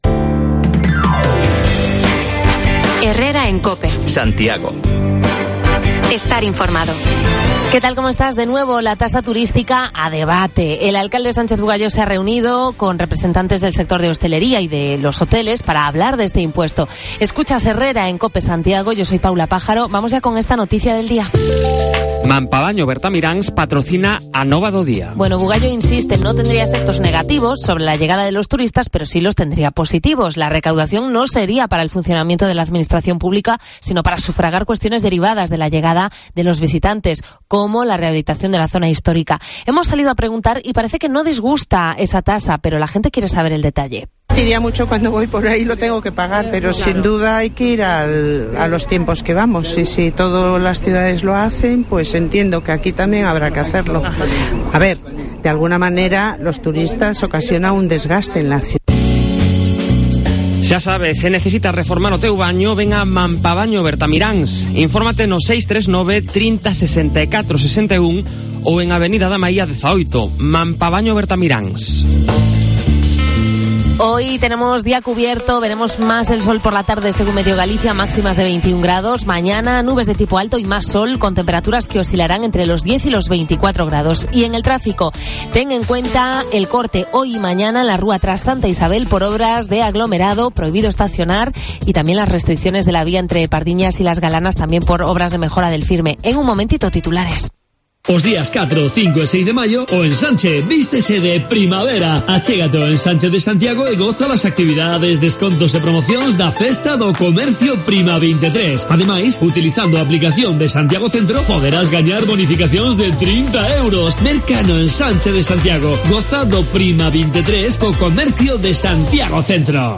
De nuevo la tasa turística a debate en Santiago. Consultamos la opinión en la calle